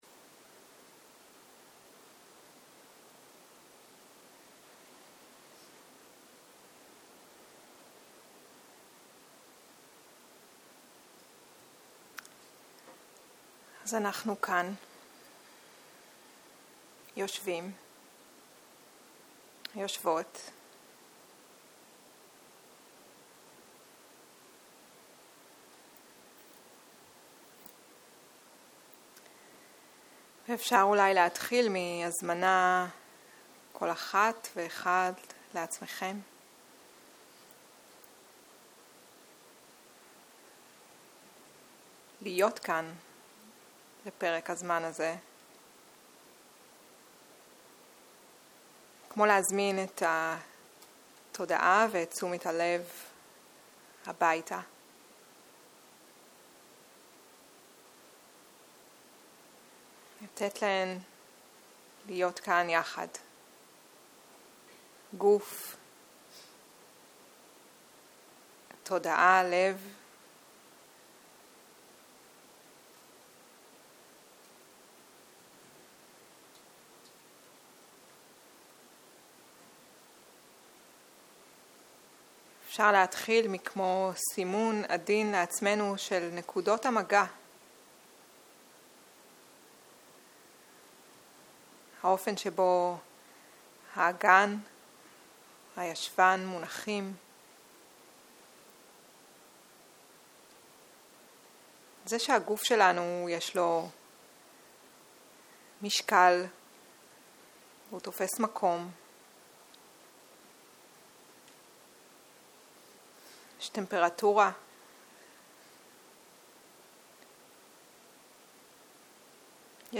צהרים - מדיטציה מונחית
סוג ההקלטה: שיחת הנחיות למדיטציה